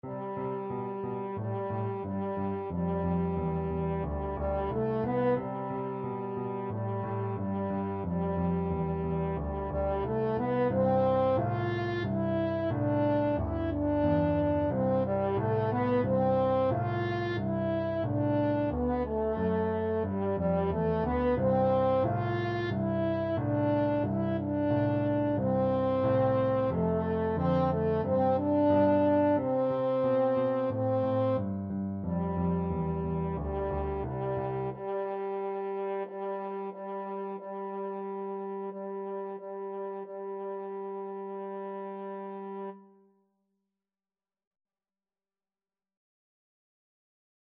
A beginners piece with a rock-like descending bass line.
March-like = 90
4/4 (View more 4/4 Music)
Pop (View more Pop French Horn Music)